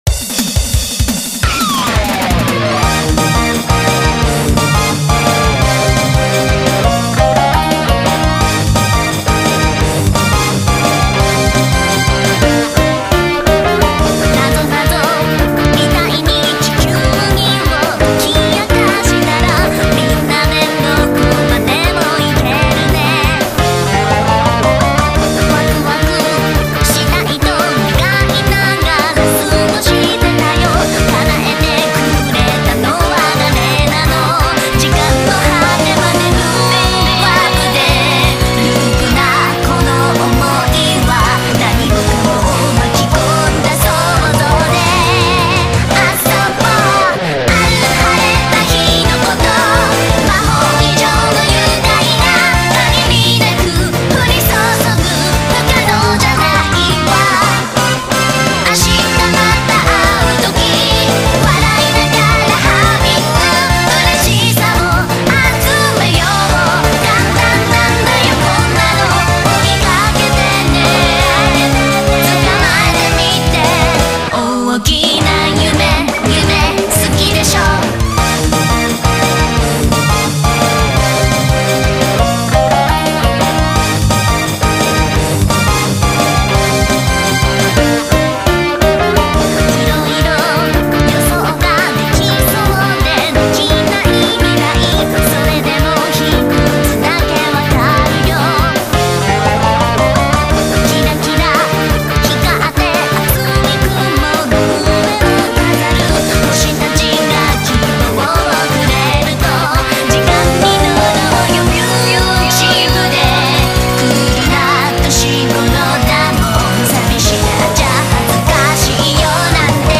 バンドチック　アレンジ　Remixです。
ギターを弾きなおしてませんが、左側に振って、
ベースにWarp VSTを使ってdriveを掛けて右側に振りました。